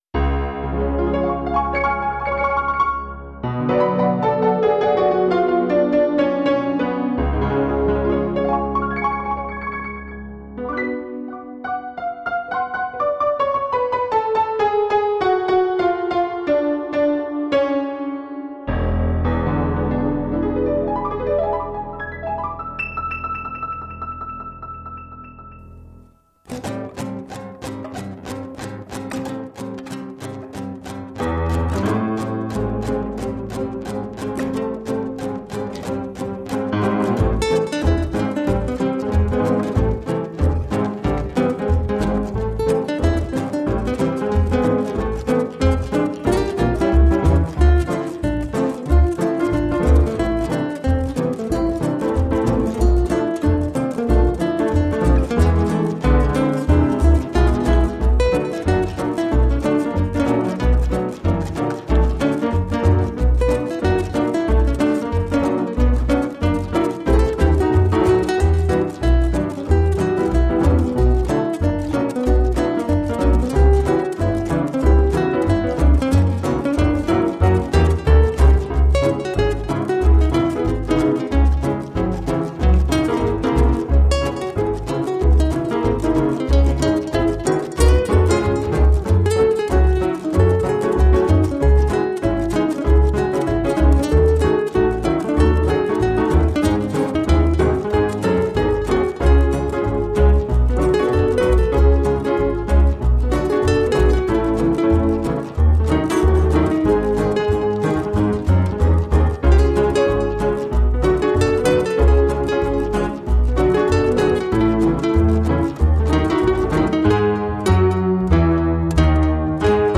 with a Manouche/ragtime styles
Tagged as: Jazz, World, Background Mix, World Influenced